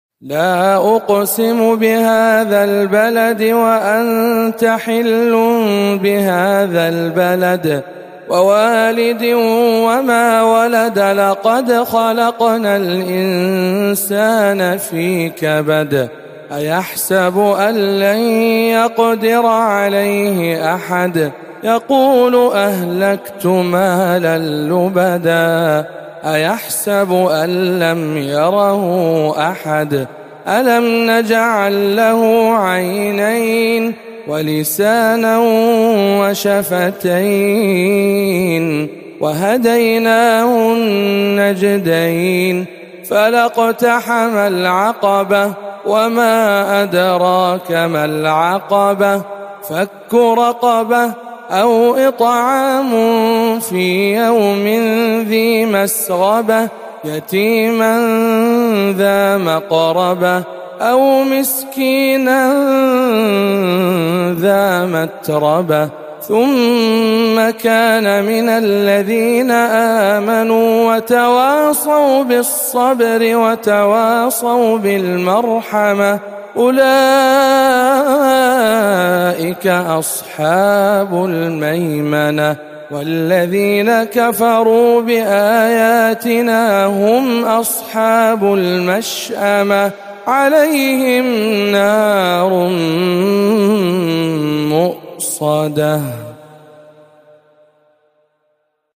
سورة البلد بجامع أم الخير بجدة